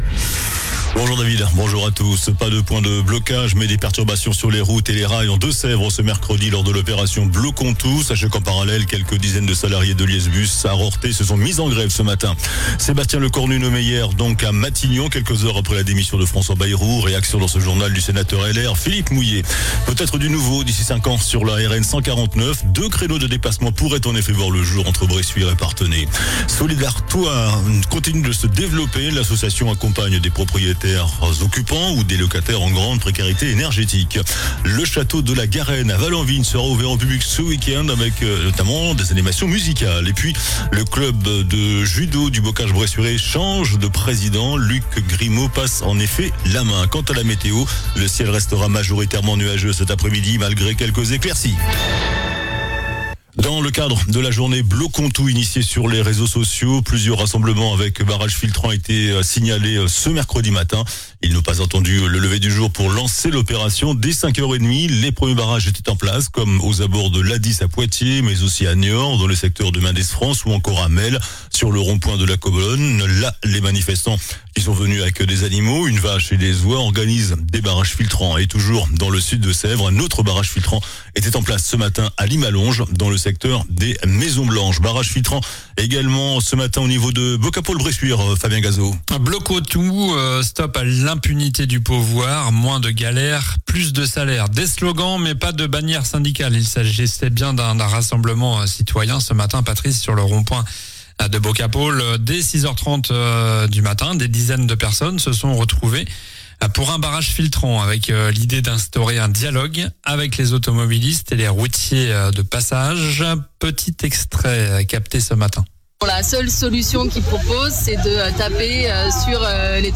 JOURNAL DU MERCREDI 10 SEPTEMBRE ( MIDI )
Quelques 10aine de salariés d'Heuliez Bus à Rorthais se sont mis en grève ce matin. Sébastien Lecornu nommé hier à Matignon quelques heures après la démission de François Bayrou. Réaction du sénateur LR deux-sévrien Philippe Mouiller Peut-être du nouveau d'ici 5 ans sur la RN 149.